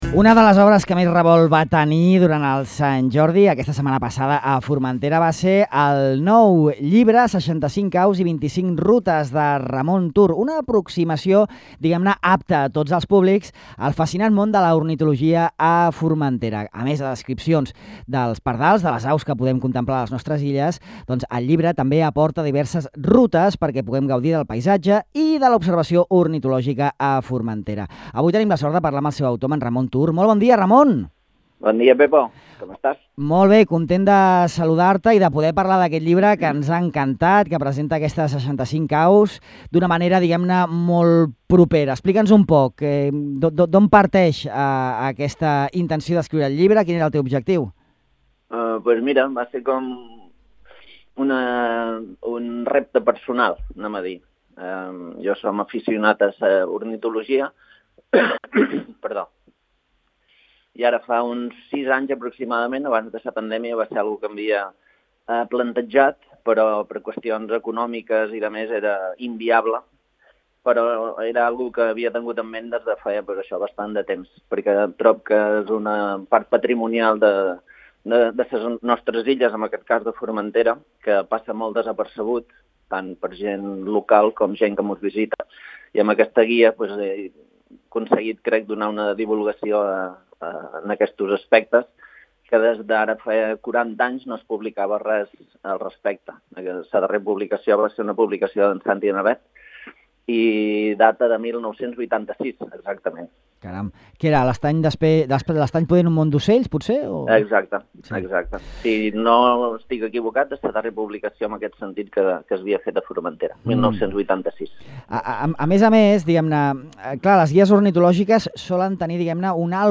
En aquest reproductor podeu escoltar l’entrevista que li hem fet a Ràdio Illa: